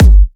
edm-kick-79.wav